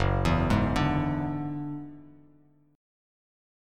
F#7sus2#5 chord